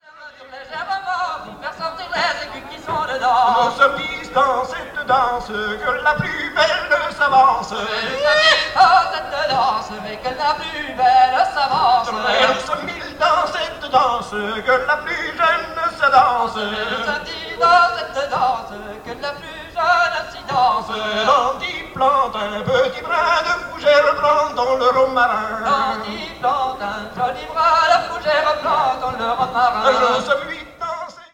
Entendu au fest-noz de Monterfil en juin 88